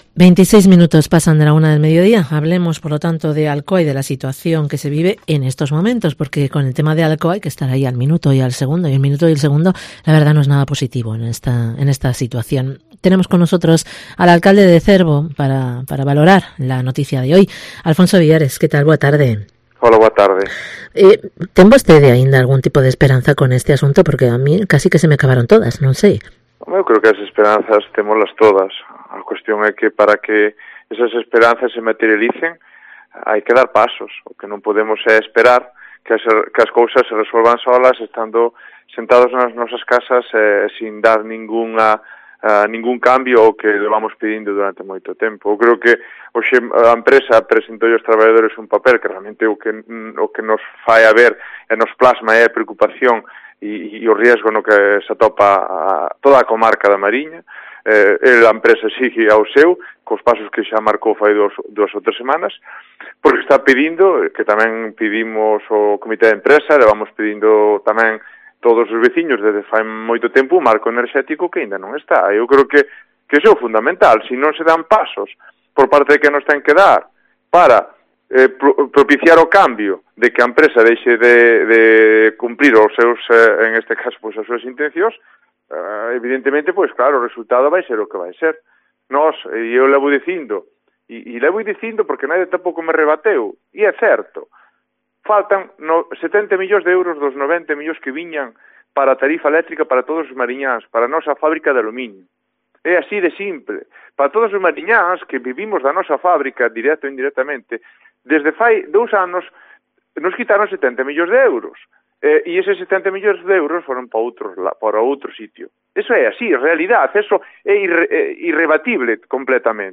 Entrevista con ALFONSO VILLARES, alcalde de Cervo